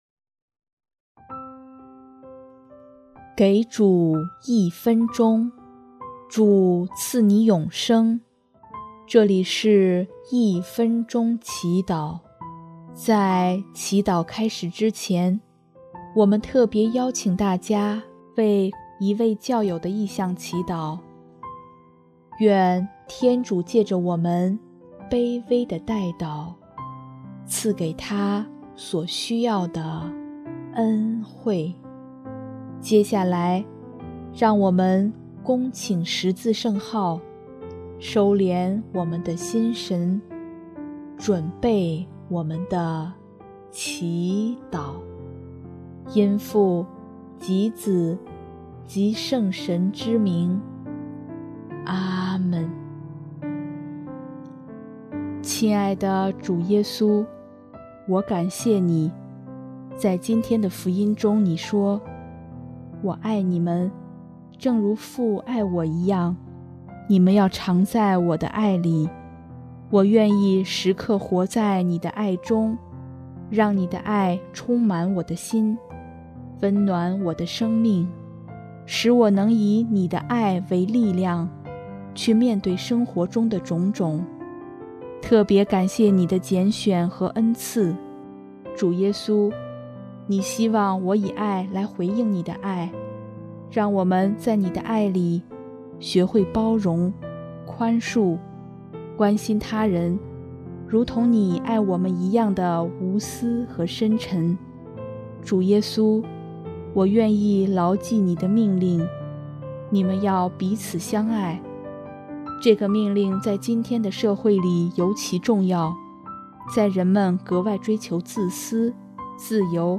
【一分钟祈祷】|5月14日 彼此相爱